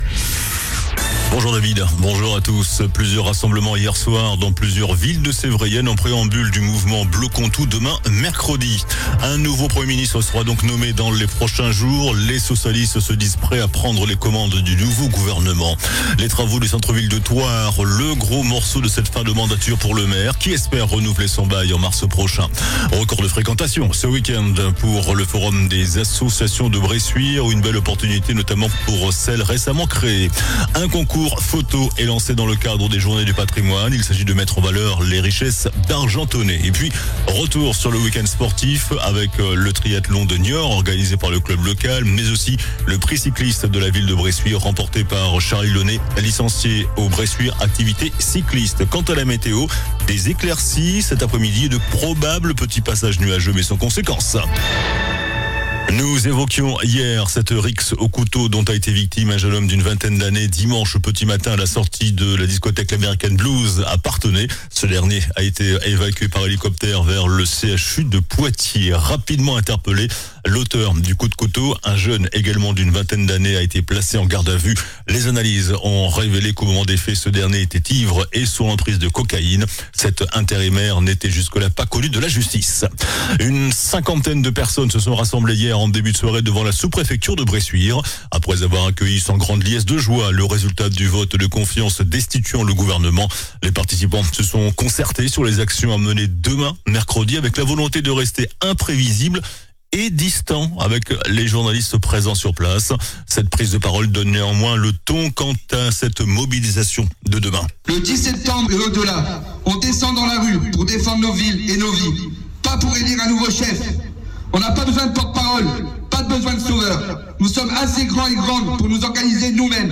JOURNAL DU MARDI 09 SEPTEMBRE ( MIDI )